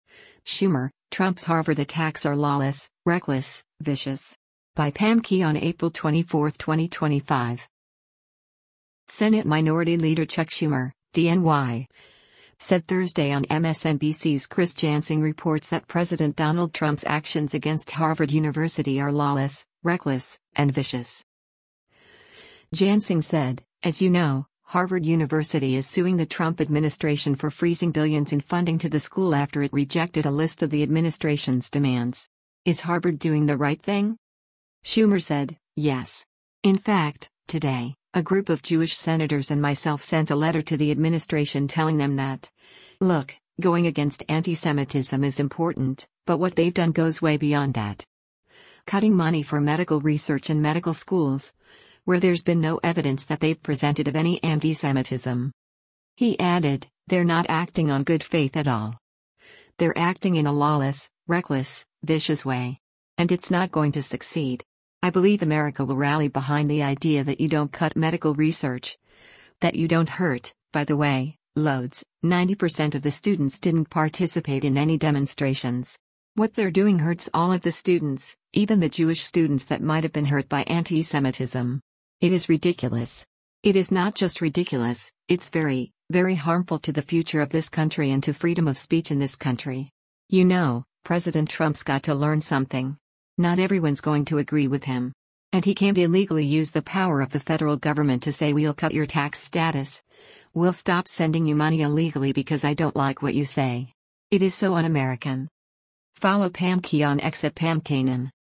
Senate Minority Leader Chuck Schumer (D-NY) said Thursday on MSNBC’s “Chris Jansing Reports” that President Donald Trump’s actions against Harvard University are lawless, reckless, and vicious.